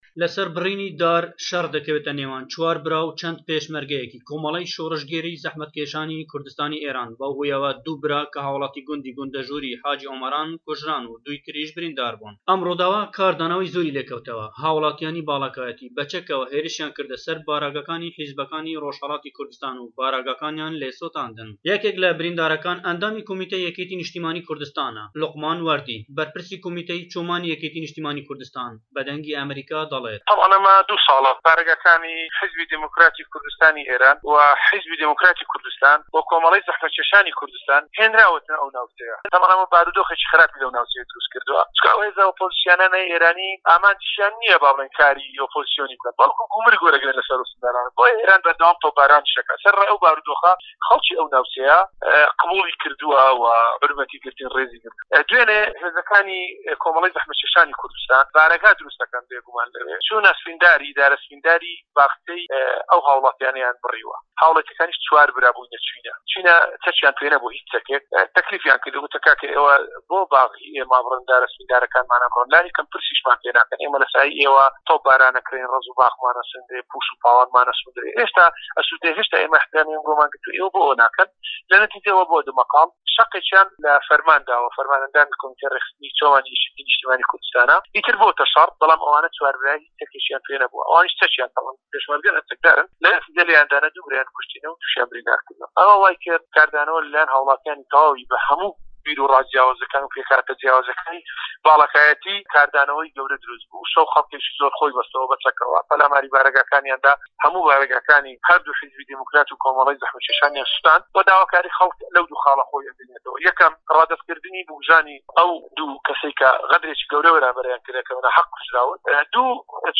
ڕاپۆرت_ رووداوی گونده‌ژوور